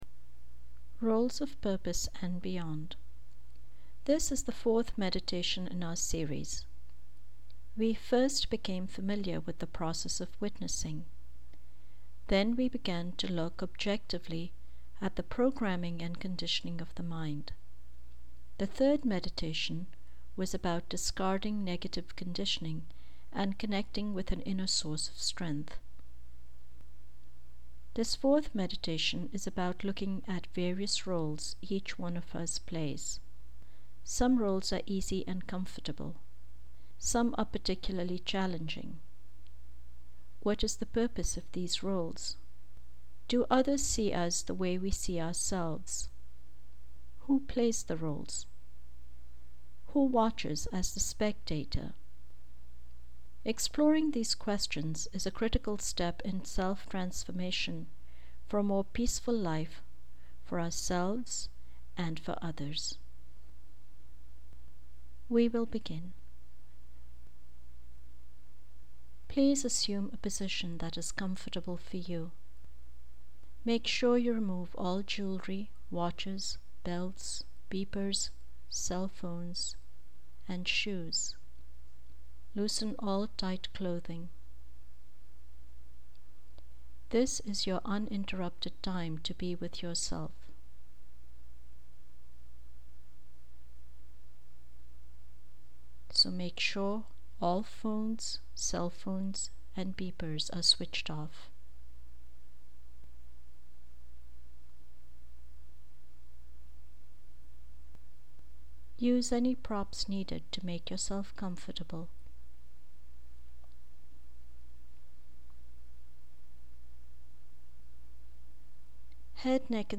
This is the fourth meditation in our series.